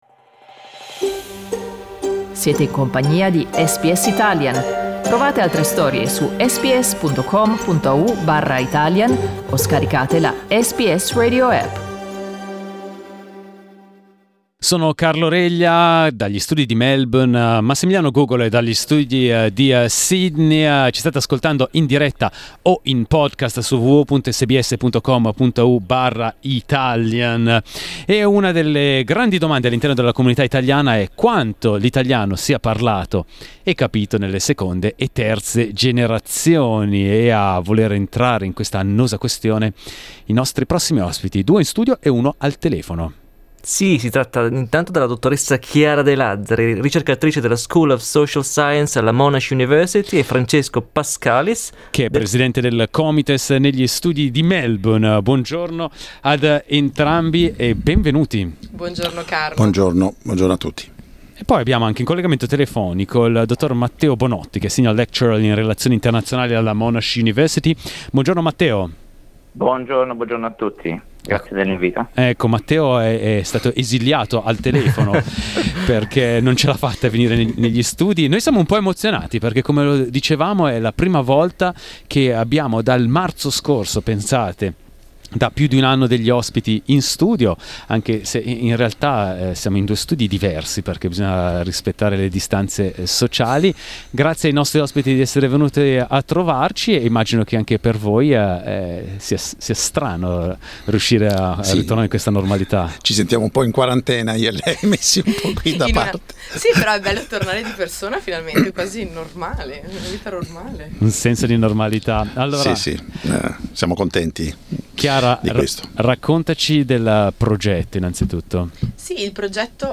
Ascolta l'intervista ai ricercatori su SBS Italian.